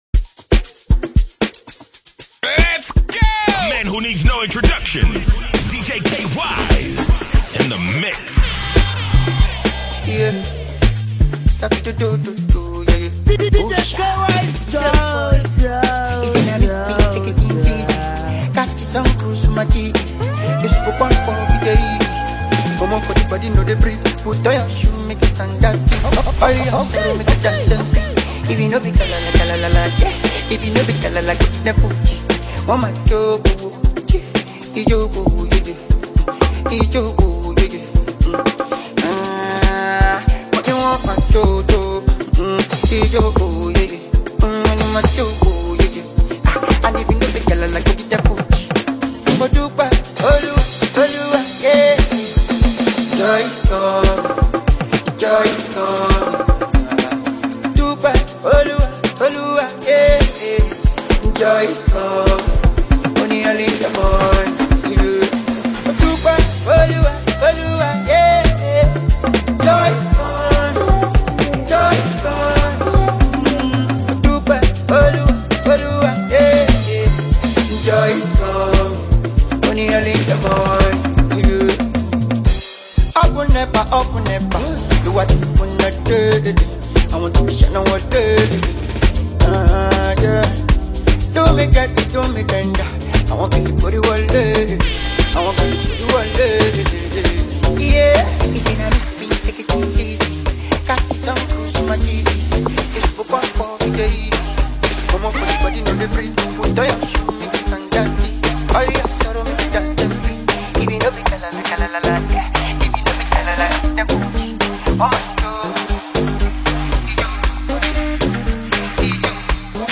Nigerian celebrity disc jockey